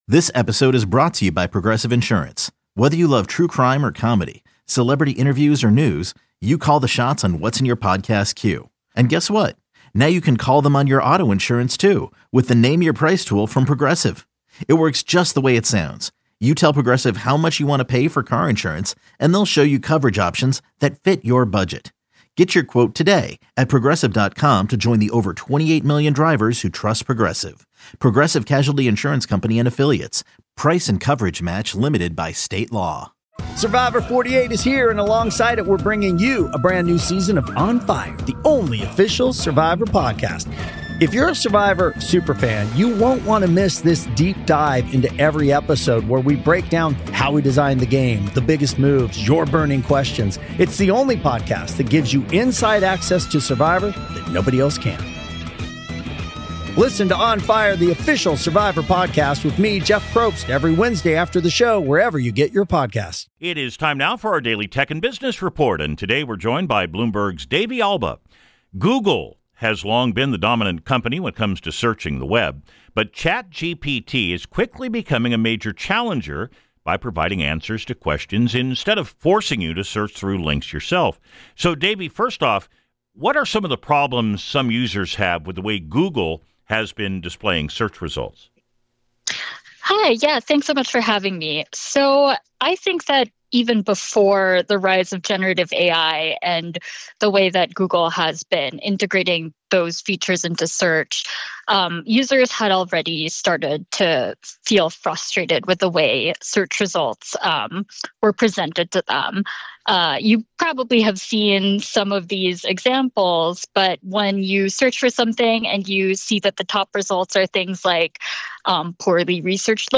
Every weekday, Emily Chang discusses the biggest news from Silicon Valley and the intersection of tech and business with one of KCBS Radio's leading anchors.